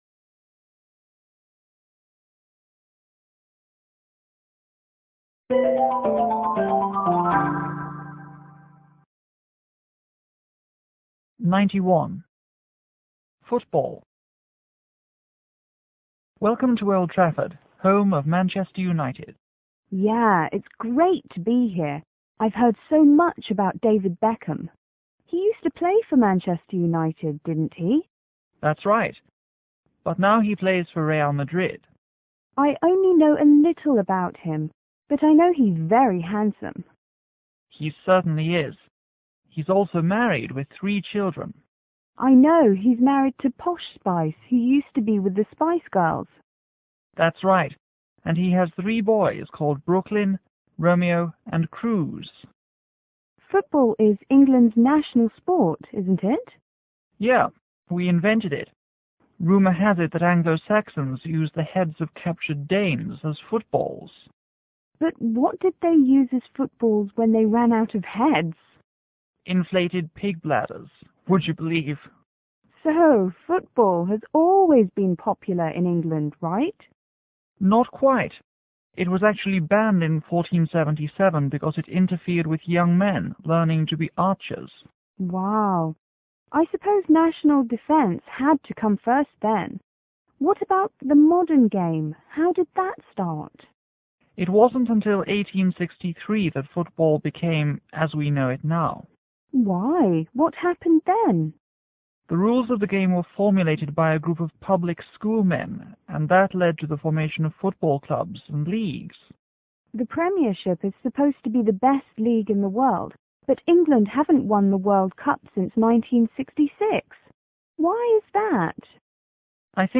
F:Football fan        T:Tourist